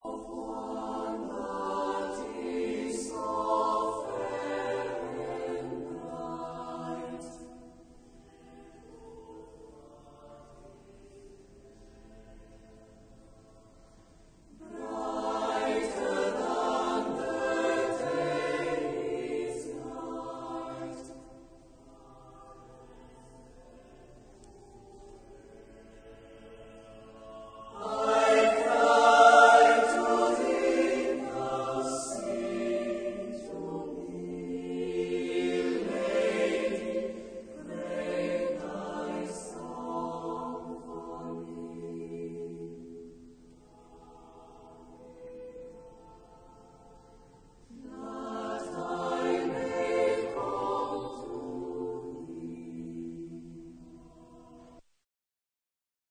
Genre-Style-Forme : Sacré ; Hymne (sacré)